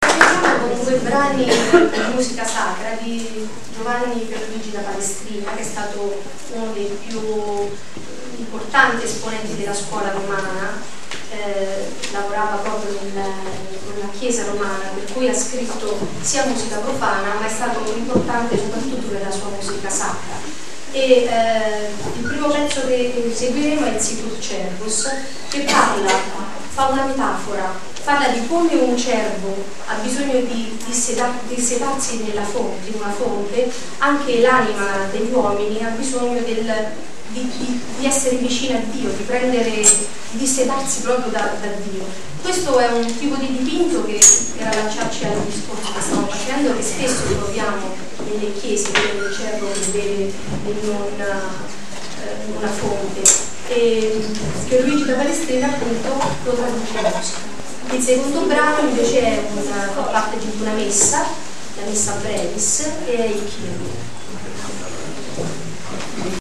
Presentazione dei brani